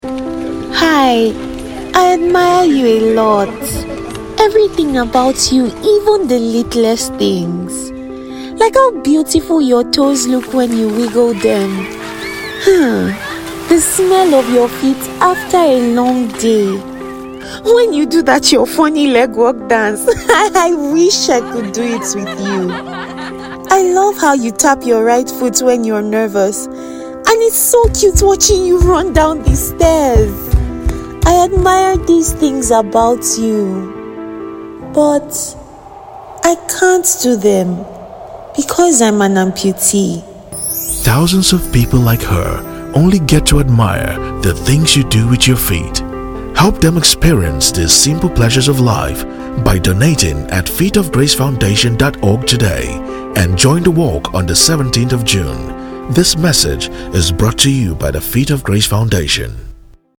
PLAY RADIO AD